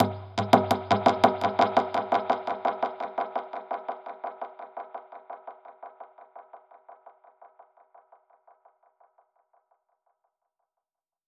Index of /musicradar/dub-percussion-samples/85bpm
DPFX_PercHit_B_85-08.wav